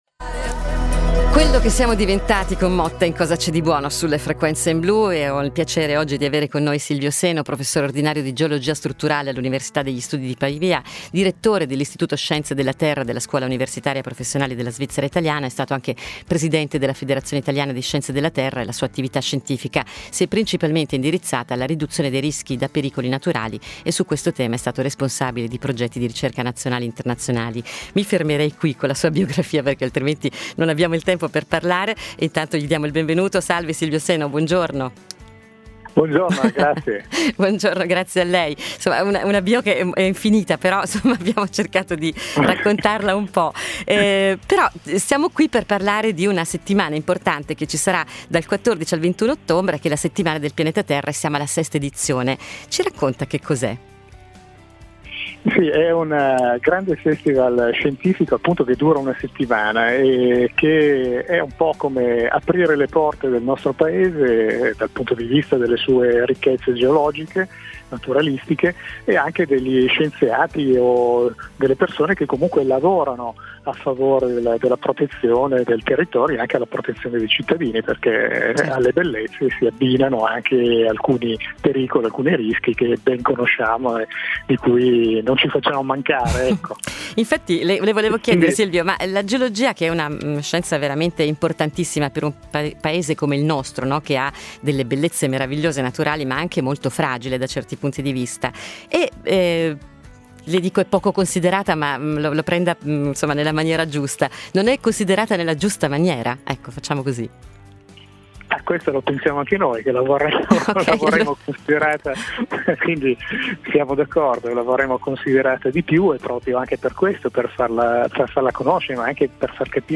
Servizio su Settimana del Pianeta Terra